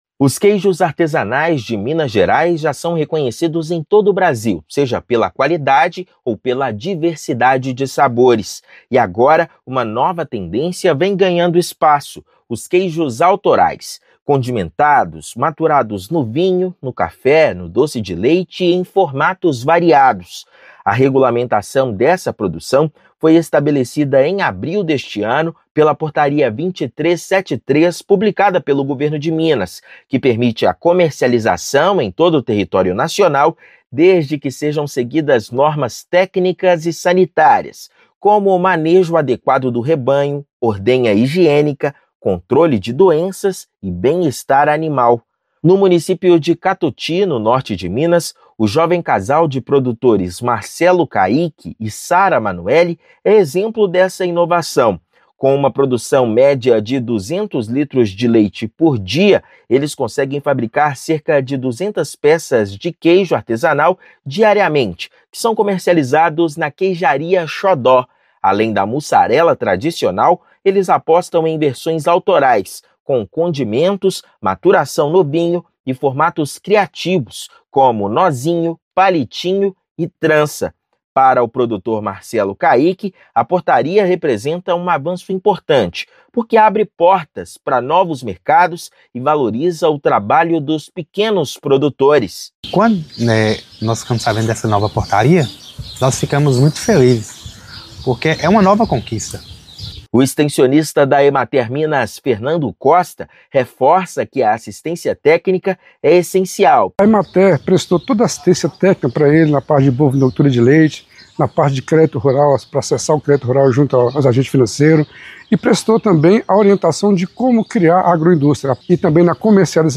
Portaria regulamentada pelo governo do estado permite a comercialização do produto em todo o país. Ouça matéria de rádio.